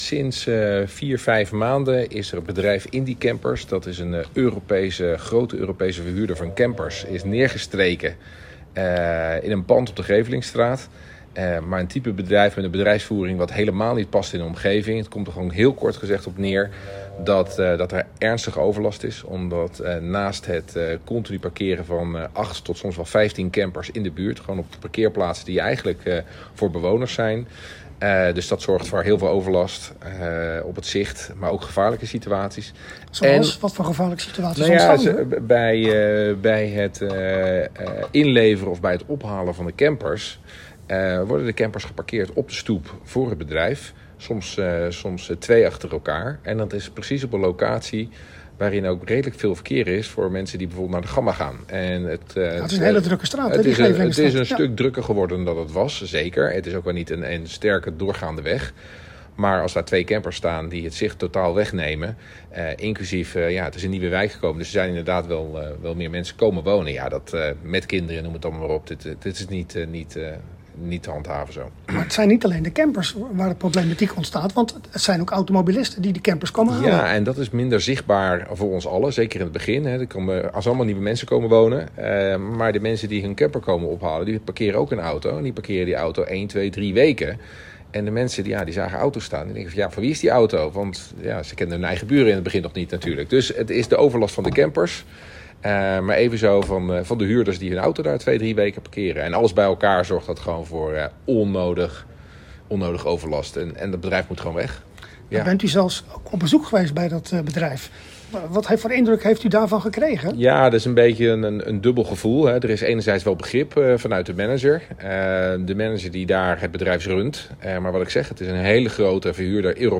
Hieronder de toelichting van de inspreker: